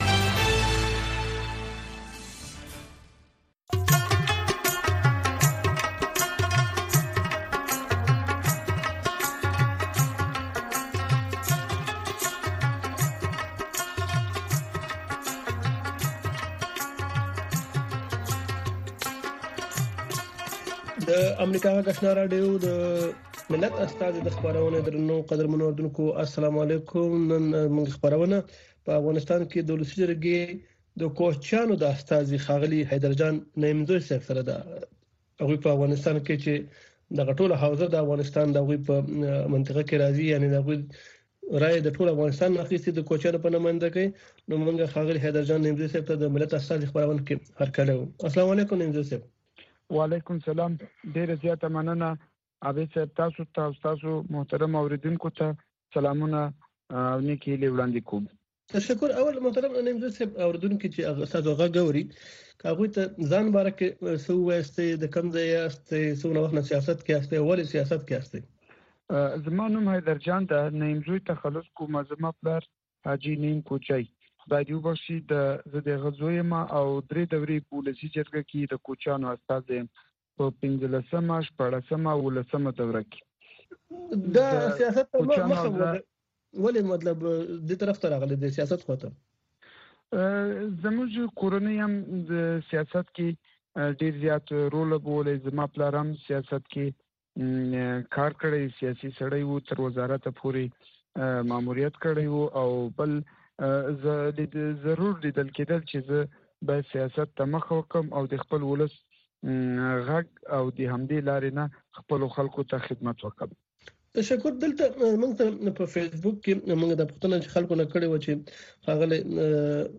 خبرې اترې
په دغې خپرونه کې د روانو چارو پر مهمو مسایلو باندې له اوریدونکو او میلمنو سره خبرې کیږي.